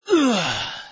man_Death.mp3